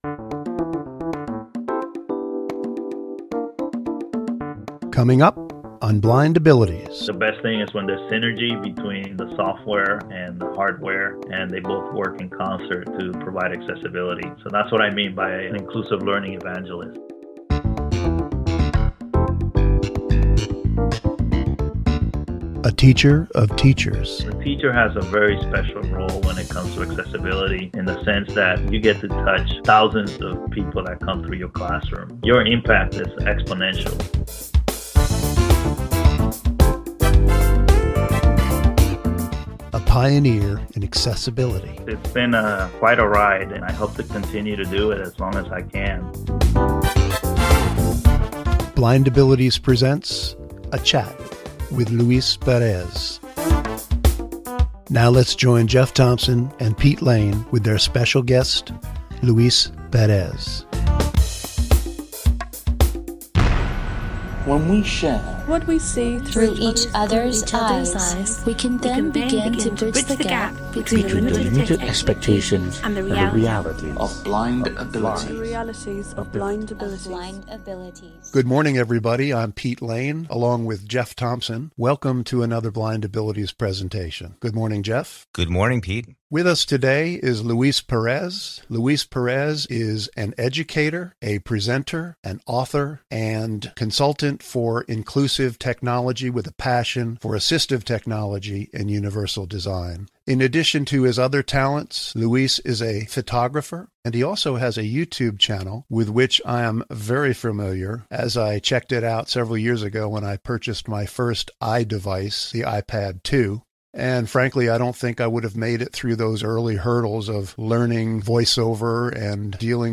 Or, you can just tune in and hear all of that: be sure to set time aside for this special interview.